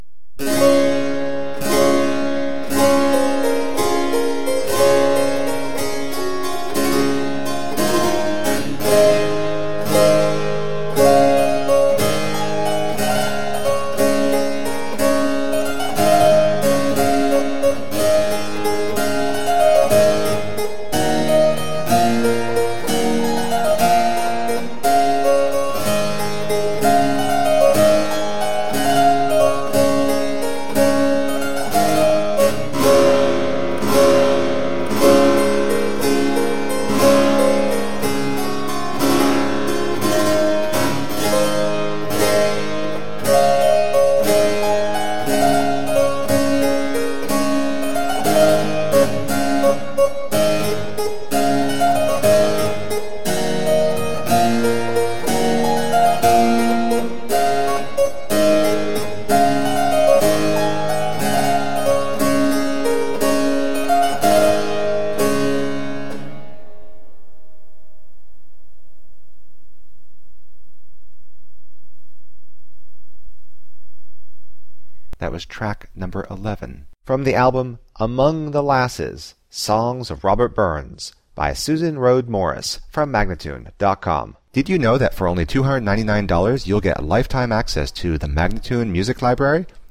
Tagged as: Classical, Folk, Celtic